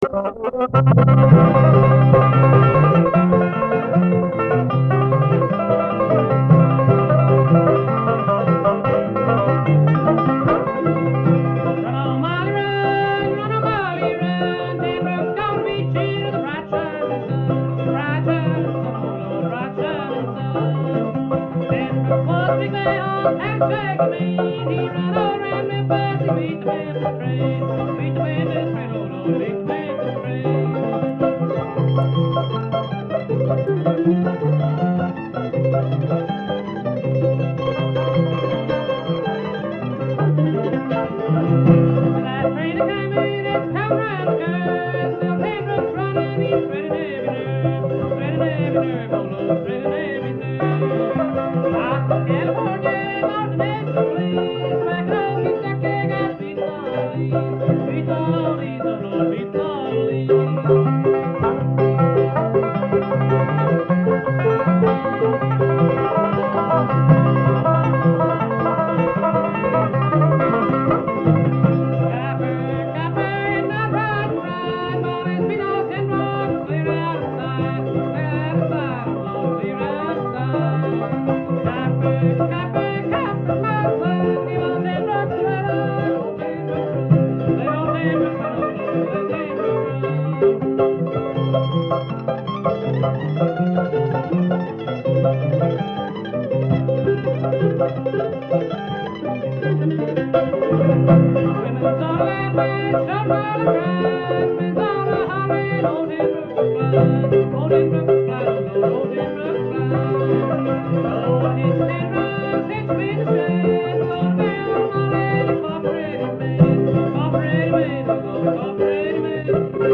was a Bluegrass band out of Berkeley
singer-mandolin wiz
banjo player
The fidelity sux, but the passion and spirit are there,  as evidenced-and I hope most of you agree-by this treatment of the Bill Monroe  chestnut,
The banjo pickin’ ain’t too shabby, either.